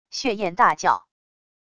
血焱大叫wav音频